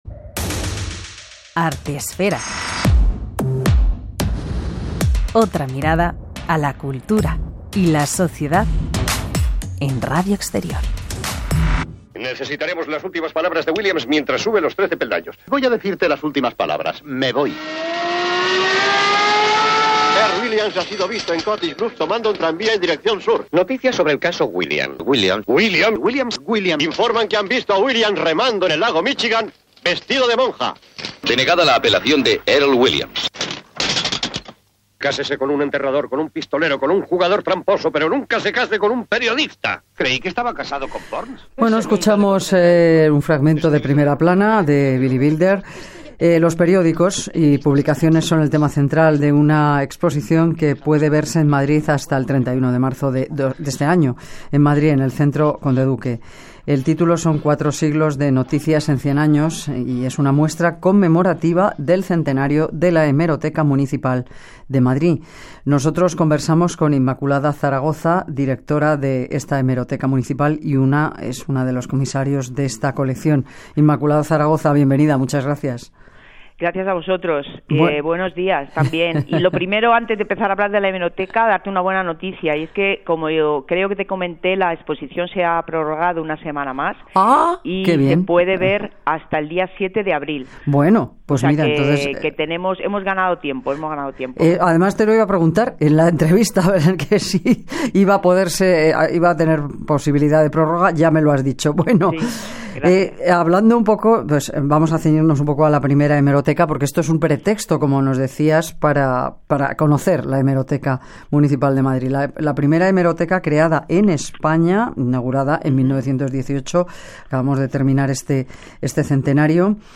EntrevistaArtesfera.mp3